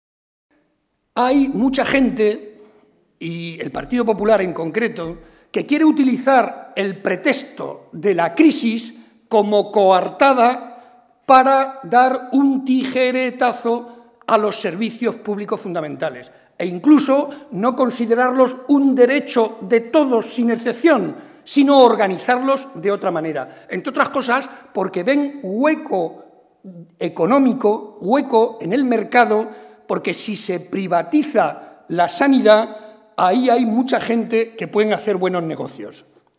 “De la misma manera que en el fútbol hasta el minuto final se puede dar la vuelta al partido, también se le puede dar la vuelta a las encuestas y a los pronósticos” ha asegurado el cabeza de lista del PSOE al Congreso, José María Barreda, durante su intervención en un acto público en la localidad de Miguelturra.
Cortes de audio de la rueda de prensa